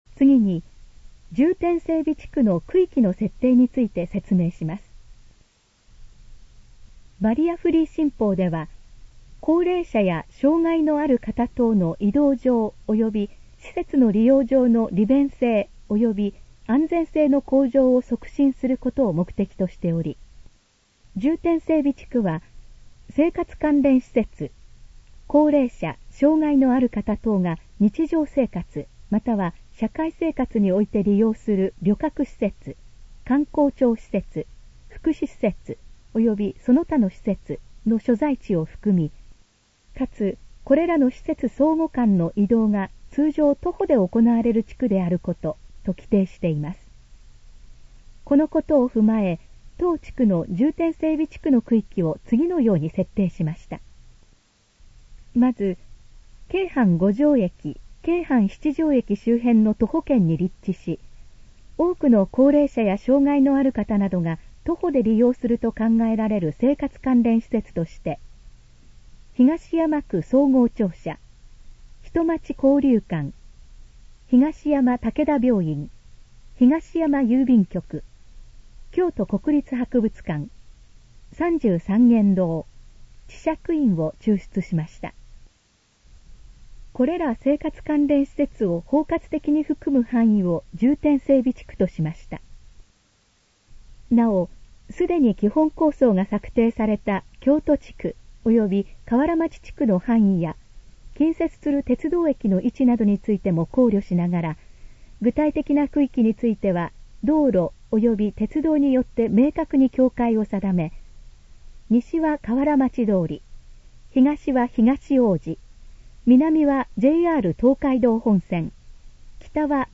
このページの要約を音声で読み上げます。
ナレーション再生 約493KB